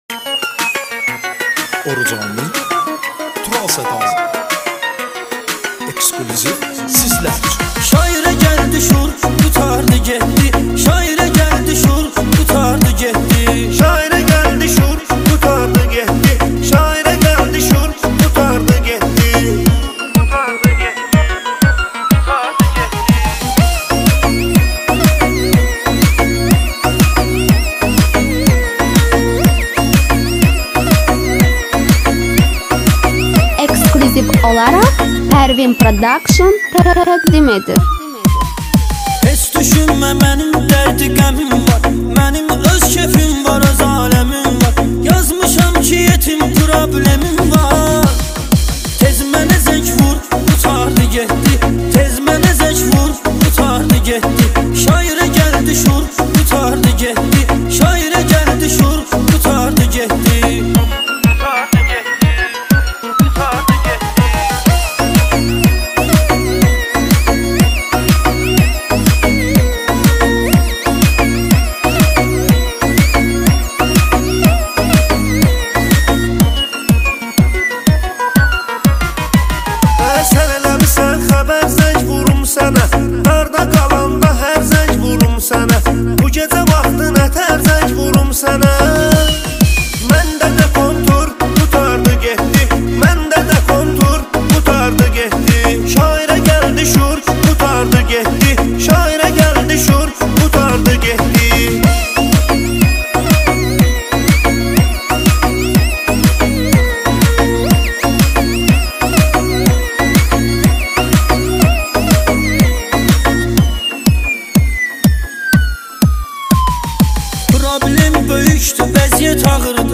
بخش دانلود آهنگ ترکی آرشیو